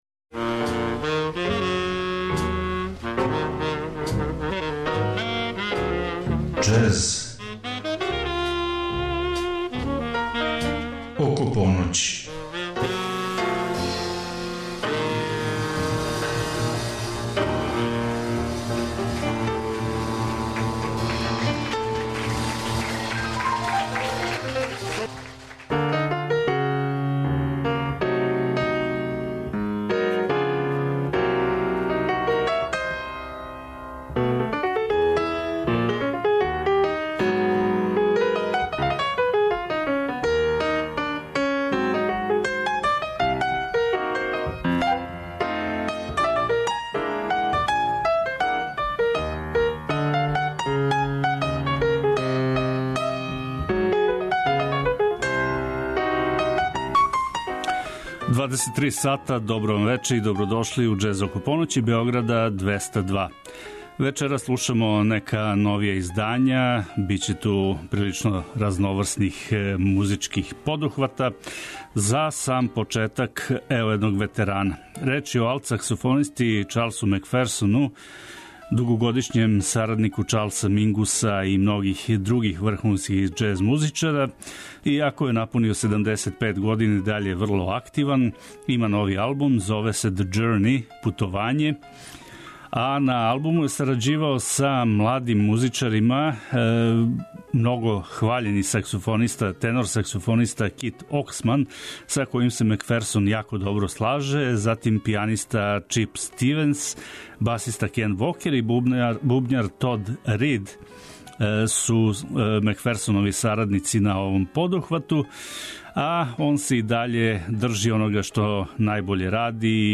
Емисија је магазинског типа и покрива све правце џез музике, од Њу Орлиенса, преко мејнстрима, до авангардних истраживања. Теме су разноврсне - нова издања, легендарни извођачи, снимци са концерата и џез клубова, архивски снимци...